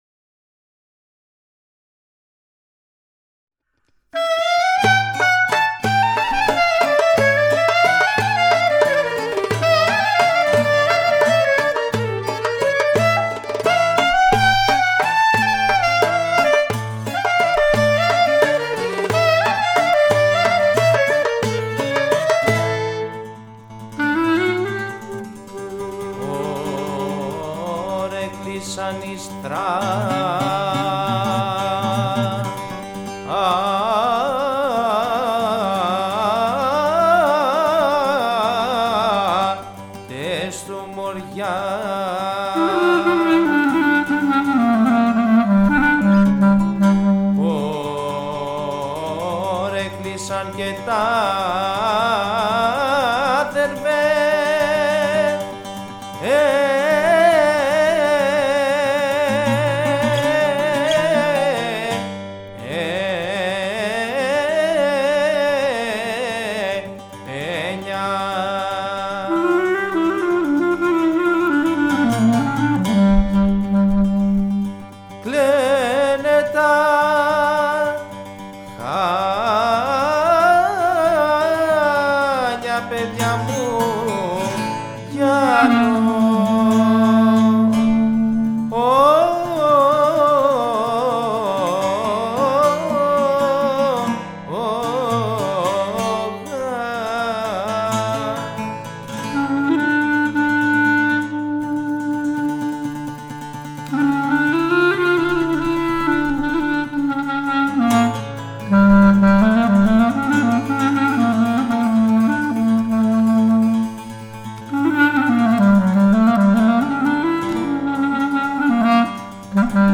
στη φωνή και το κλαρίνο
βιολί
φλογέρα
λάουτο
κρουστά
σαντούρι